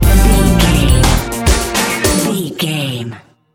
Uplifting
Aeolian/Minor
Fast
drum machine
synthesiser
electric piano